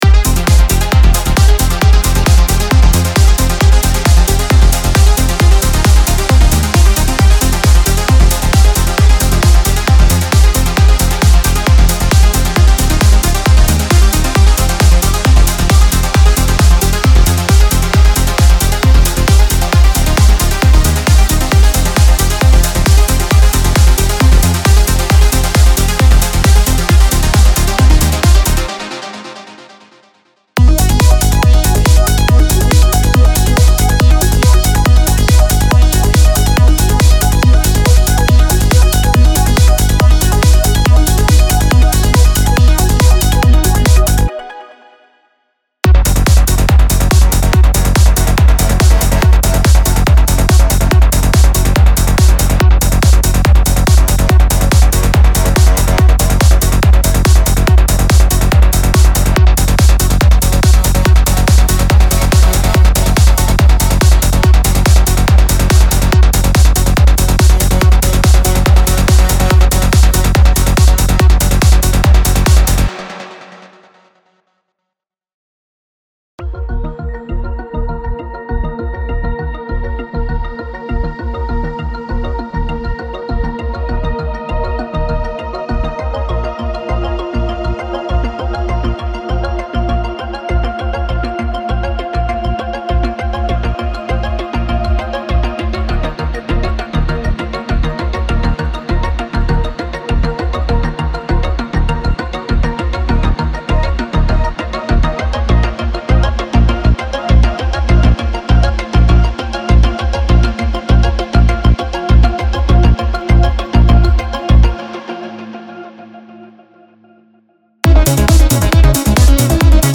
Psy-Trance Trance
CineTrance Connection Vol.3 gives you 62 intensive trance and cinematic presets for SPIRE 1.5. CineTrance Connection series are a great way to enjoy the full capabilities of Reveal Sound SPIRE with the use of the 4 Macros, The Modwheel / Pitchweel and an arsenal of advanced sound design technics.
Enjoy the Sequences & ARPs, the mighty Leads & Plucks, the otherworldy FXs and the beautiful Pads and Atmospheres inside CineTrance Connection Vol.3!